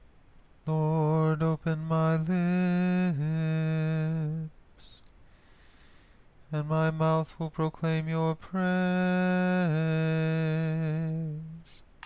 Caveat: this is not Gregorian Chant.